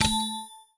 Trade Greenlight First Sound Effect